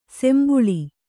♪ sembuḷi